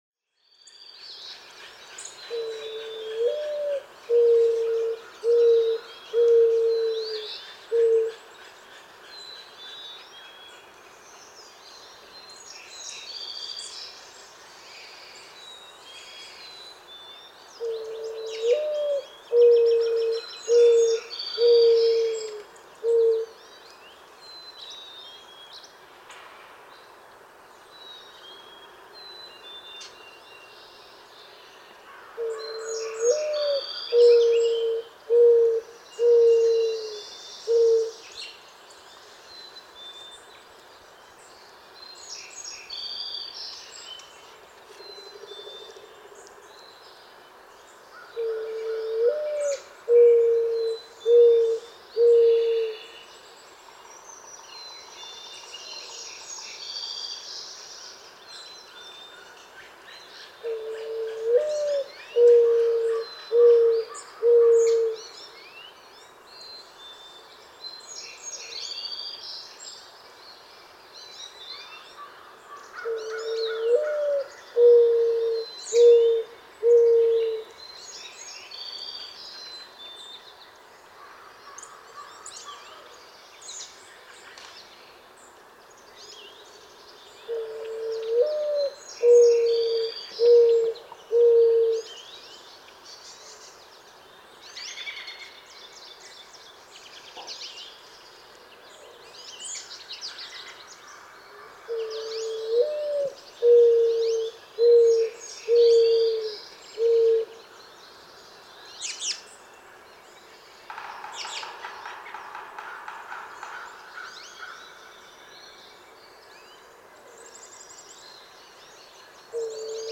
Mourning dove
♫137. The innate cooowaah, cooo, coo, coo song.
Cricket Hill, Conway, Massachusetts.
137_Mourning_Dove.mp3